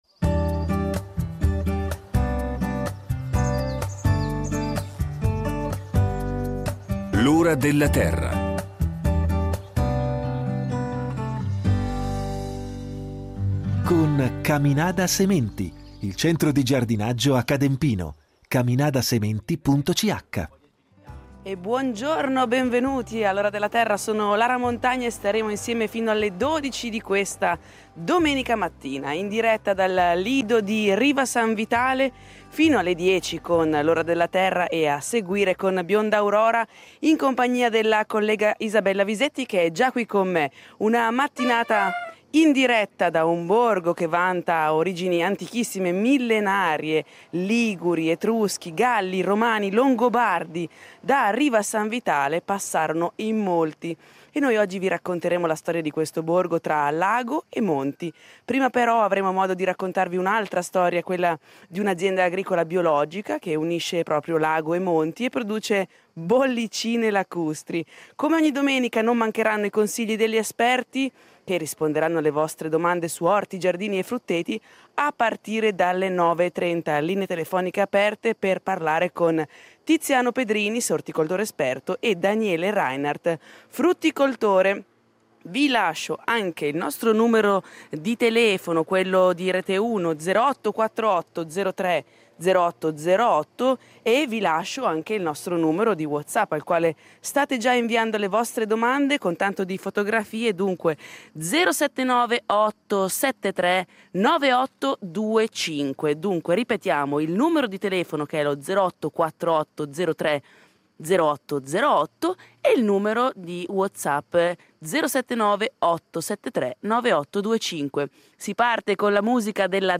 L’Ora della Terra fotograferà questo territorio partendo dal basso, dalla terra appunto, presentando una giovane azienda agricola che unisce proprio lago e monti e produce “bollicine lacustri”. Non mancheranno neppure gli esperti de L’Ora della Terra che, come ogni domenica, risponderanno alle domande del pubblico.